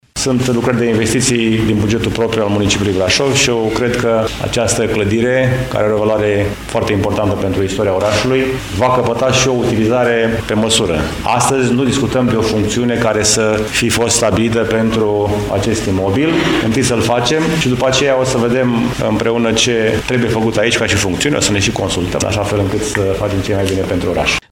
Primarul George Scripcaru a precizat că, după consolidarea clădirii, se va stabili şi viitoarea destinaţie a acesteia: